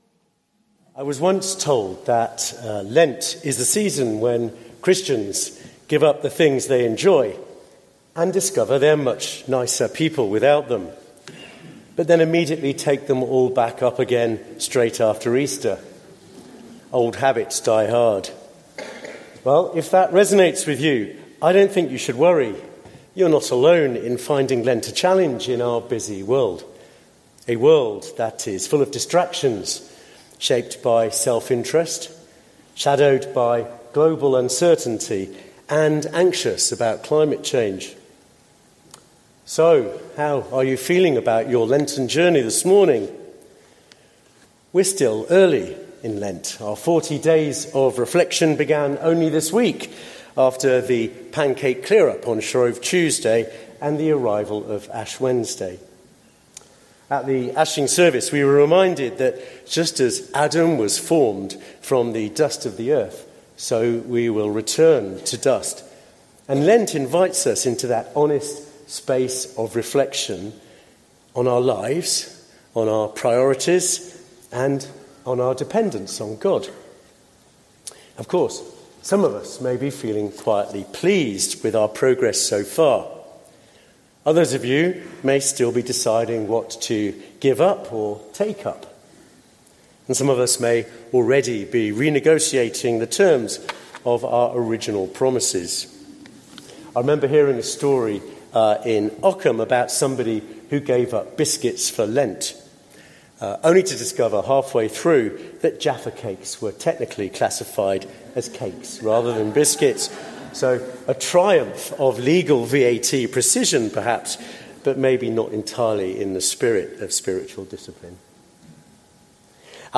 Service Type: St Andrew's at 10